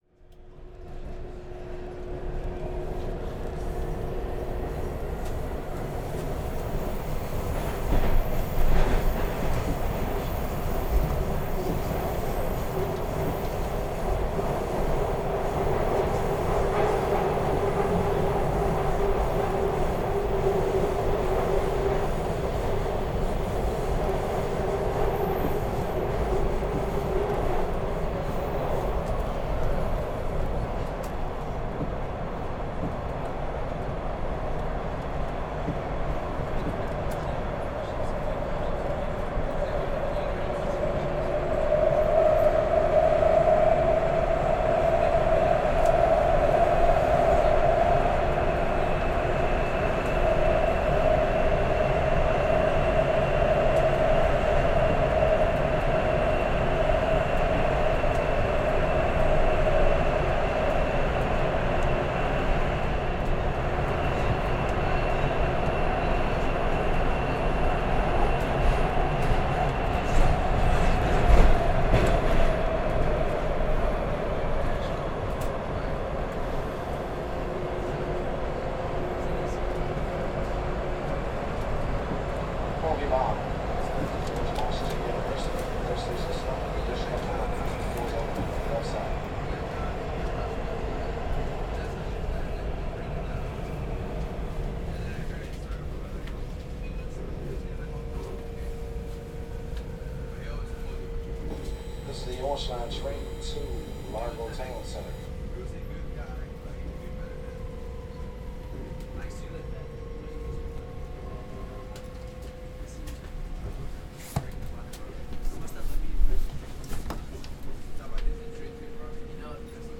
DC Metro - Interior 1
amb ambience city DC field-recording interior Metro subway sound effect free sound royalty free Sound Effects